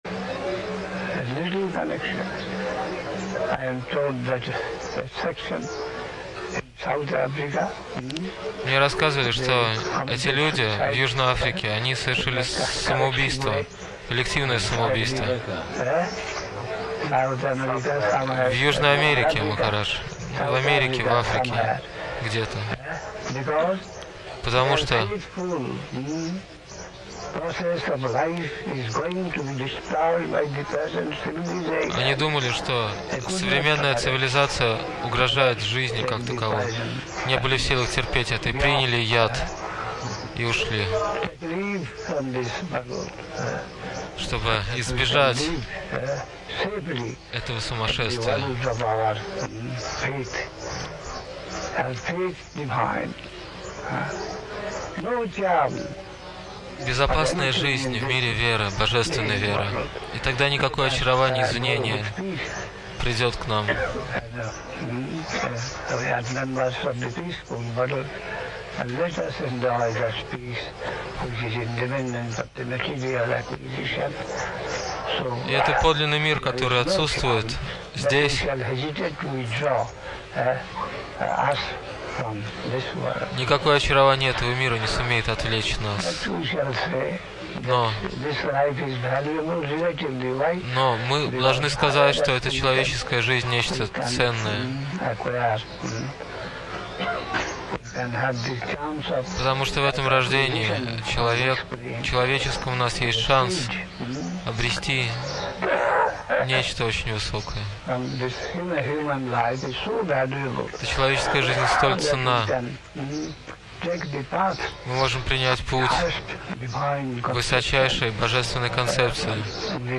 (1980-е годы. Навадвипа Дхама, Индия)